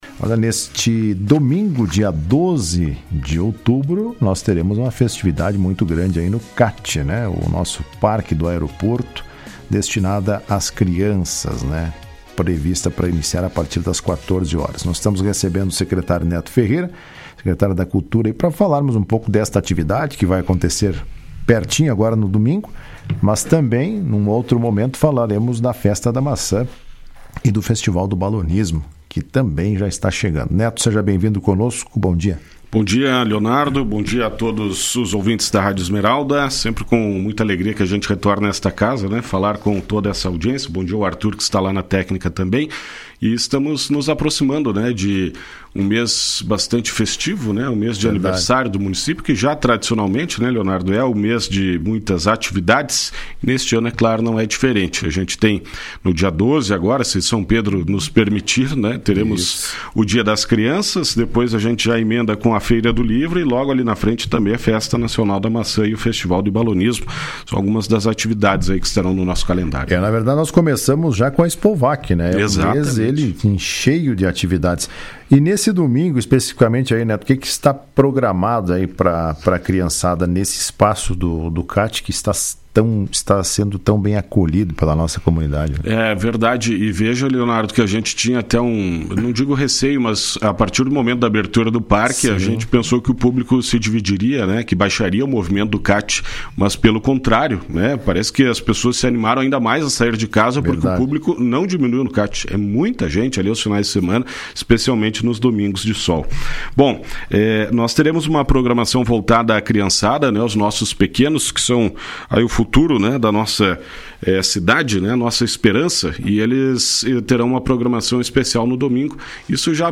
Na manhã desta quarta-feira, 08, o secretário da cultura de Vacaria, Neto Ferreira, participou do programa Comando Geral.